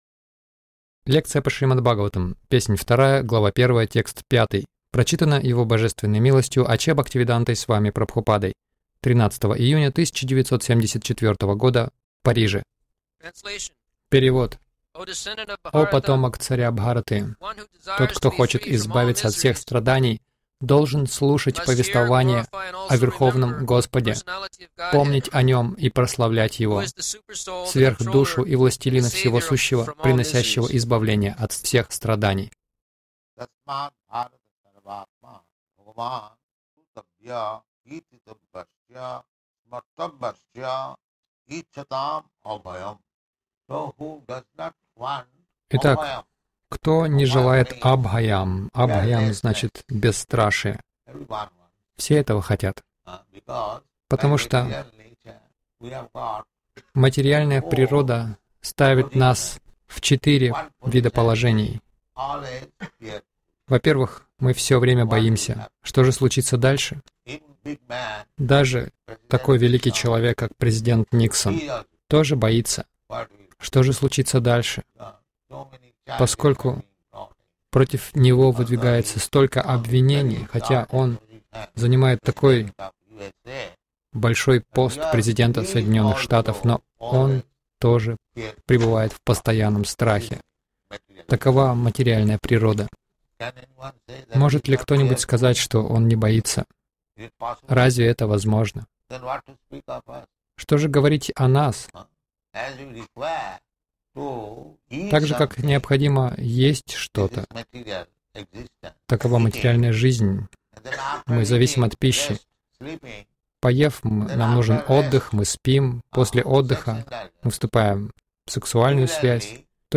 Милость Прабхупады Аудиолекции и книги 13.06.1974 Шримад Бхагаватам | Париж ШБ 02.01.05 — Как освободиться от страха Загрузка...